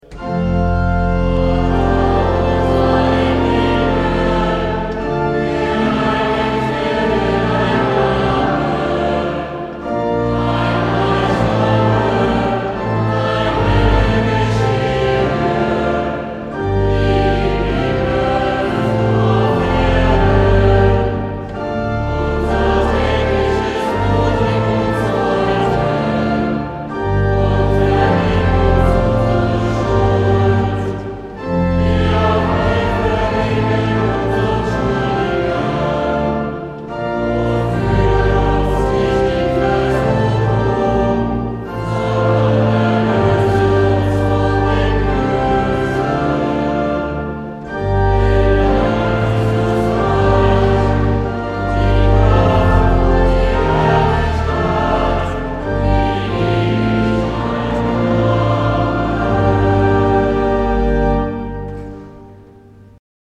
Musikalische Impressionen während der heiligen Messe zur Schlussoktav am Sonntag, den 12. September 2010